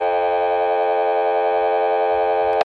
Telephone, Dial Tone ( Higher Tone )